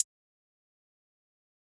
Cardiak HiHat 4.wav